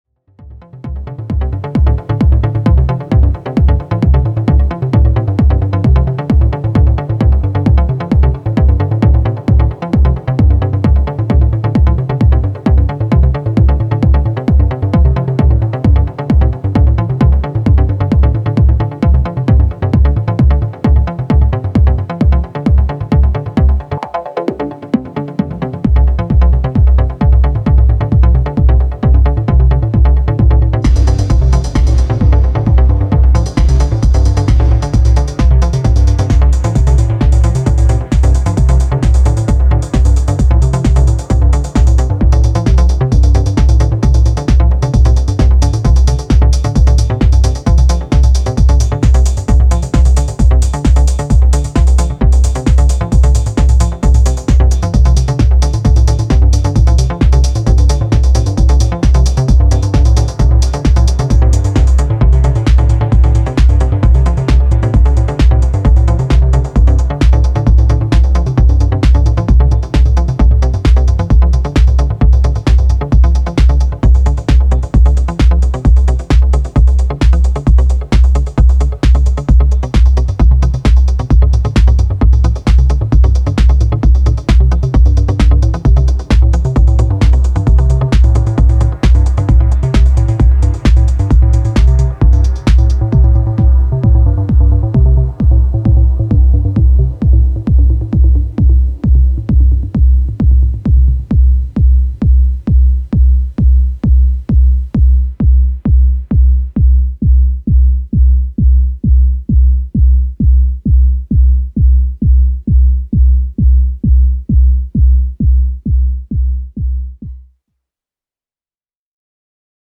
歪むほどに太く直線的なボトムと淡々とした展開でダークなインパクトを残す